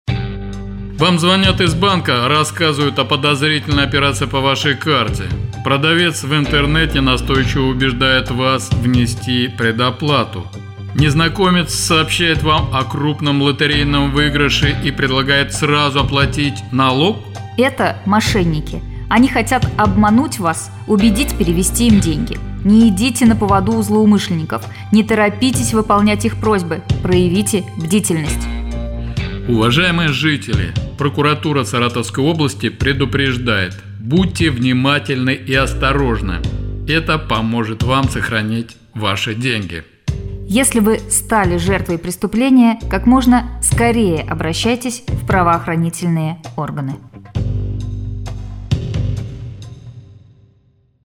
аудиоролик социальной рекламы, направленный на профилактику хищений, совершаемых дистанционно с использованием IТ-технологий.